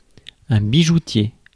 Ääntäminen
Synonyymit (slangi) marchand d'arlequins Ääntäminen France Tuntematon aksentti: IPA: /bi.ʒu.tje/ Haettu sana löytyi näillä lähdekielillä: ranska Käännös Substantiivit 1. joyero {m} Suku: m .